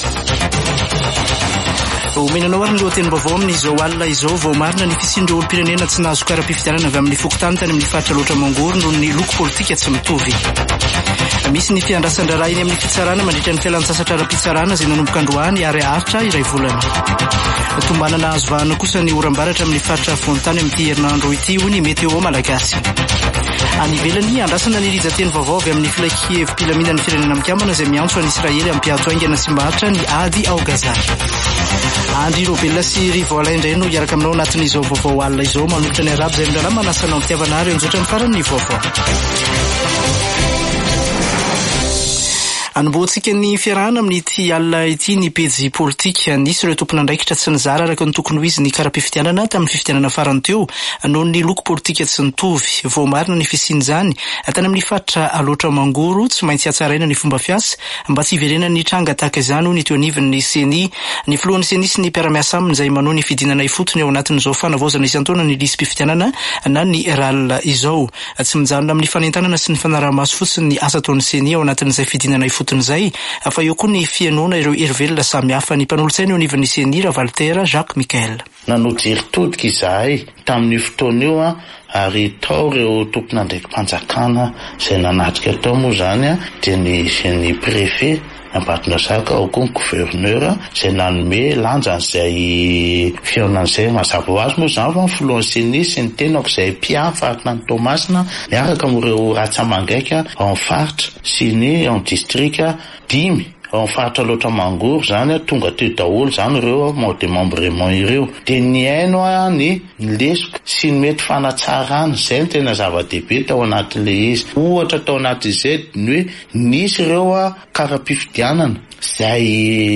[Vaovao hariva] Alatsinainy 12 desambra 2023